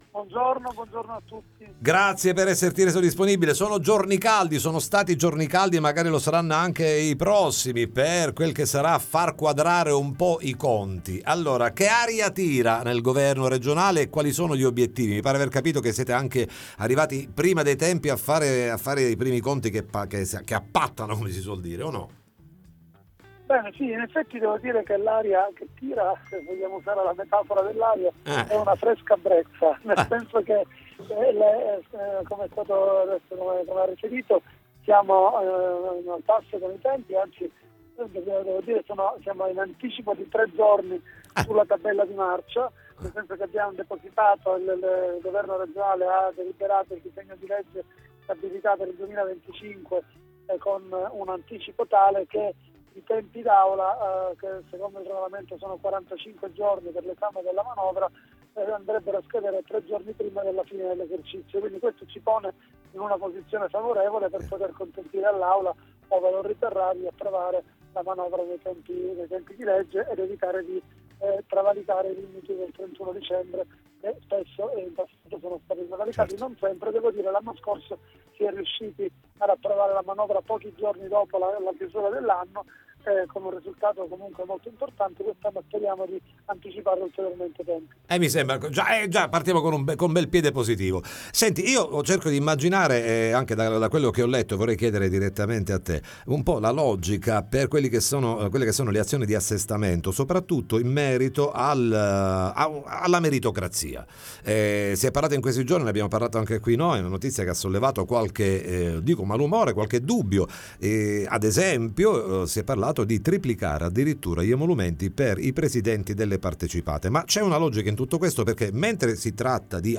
Manovra di stabilità, ne parliamo con Alessandro Dagnino, ass. reg. all’economia